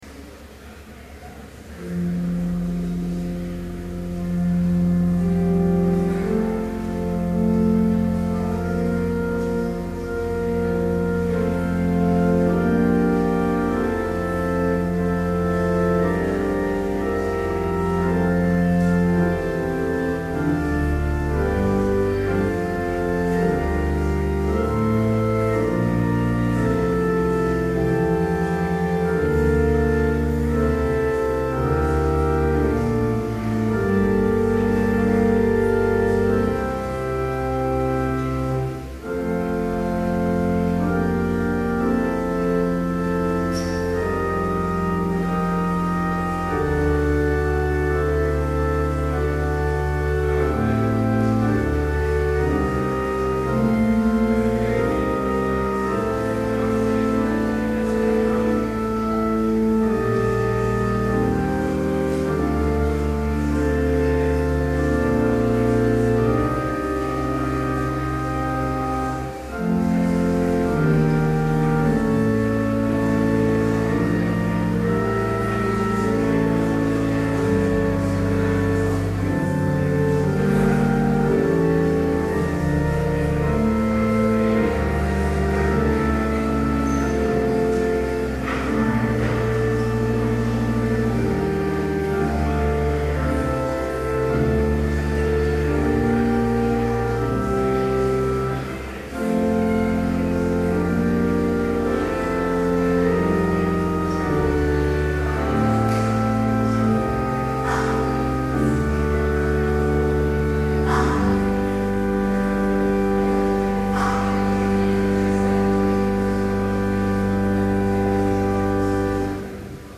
Complete service audio for Chapel - March 20, 2012